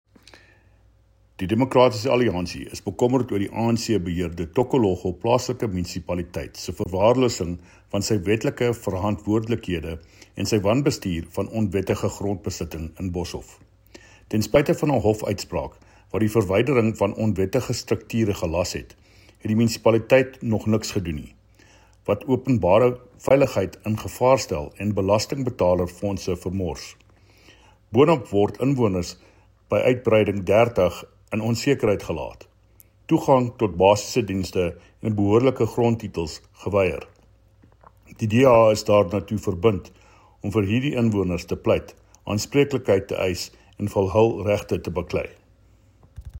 Afrikaans soundbites by David Mc Kay MPL and Sesotho soundbite by Jafta Mokoena MPL.